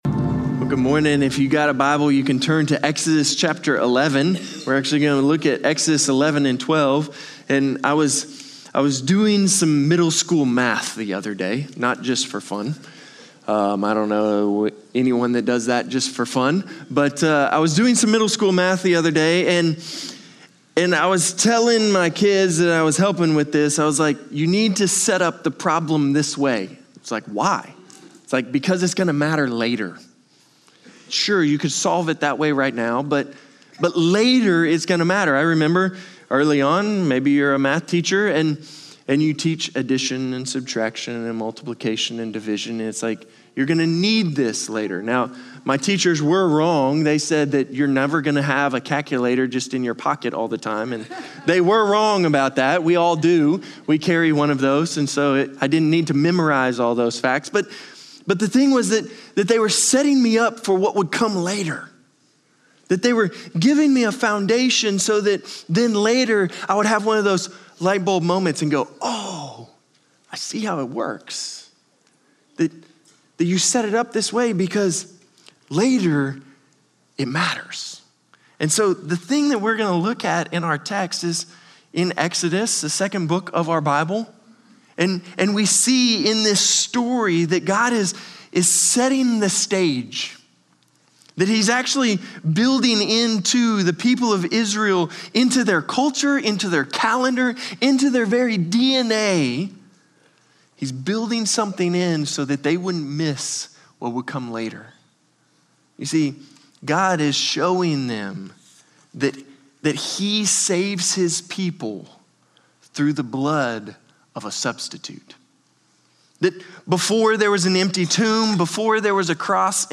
Norris Ferry Sermons Apr. 5, 2026 -- Exodus 11:1-12:28--Easter Apr 05 2026 | 00:28:07 1x 00:00 / 00:28:07 Subscribe Share Spotify RSS Feed Share Link Embed